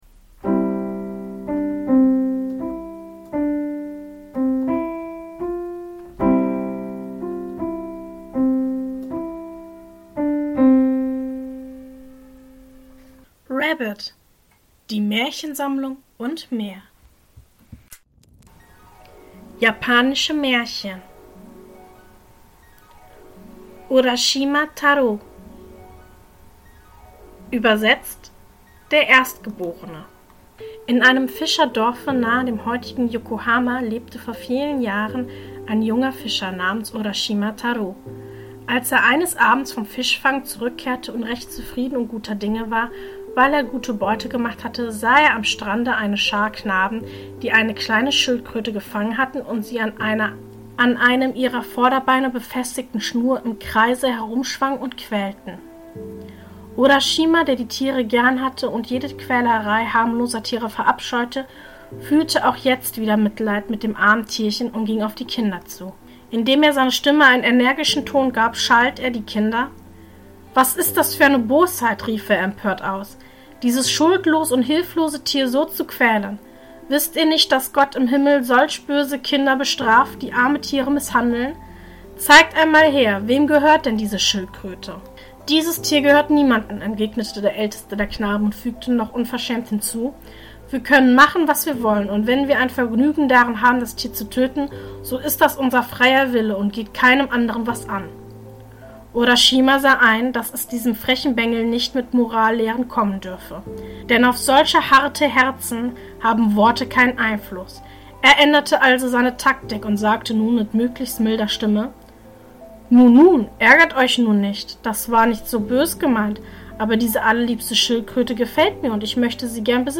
In der heutigen Folge lese ich Folgendes vor: 1. Urashima Taro Mehr